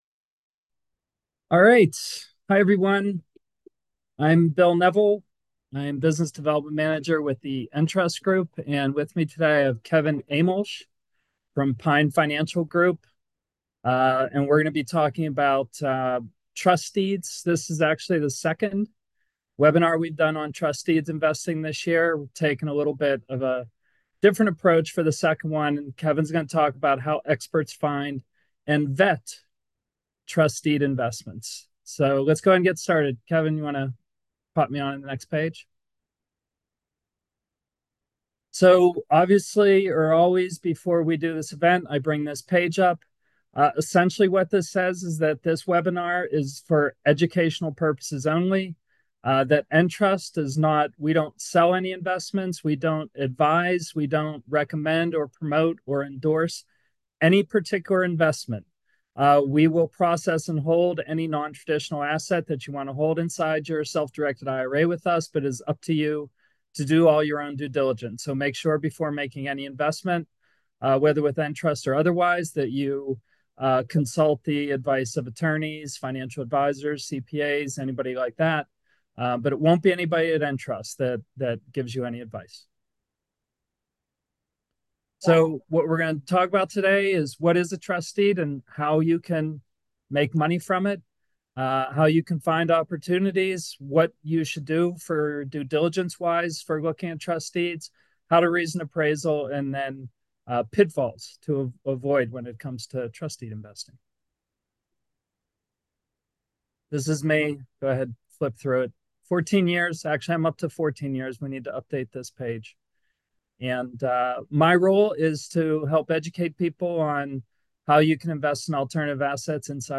Webinar_September_2024_Audio_Replay.m4a